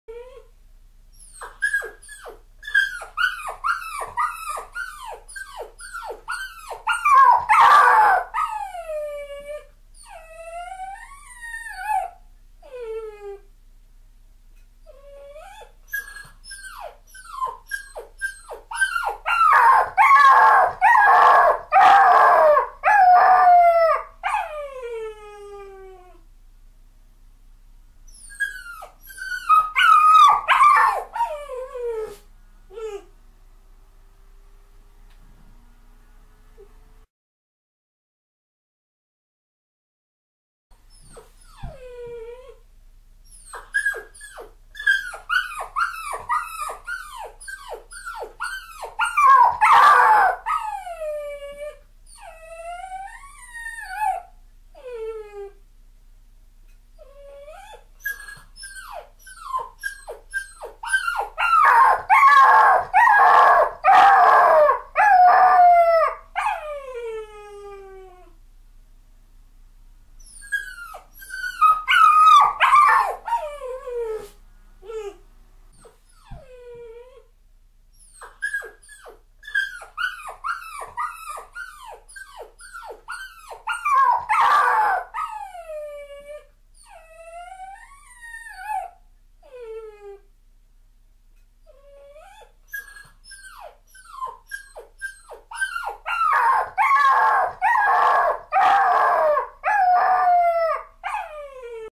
دانلود آهنگ گریه کردن سگ 2 از افکت صوتی انسان و موجودات زنده
دانلود صدای گریه کردن سگ 2 از ساعد نیوز با لینک مستقیم و کیفیت بالا
جلوه های صوتی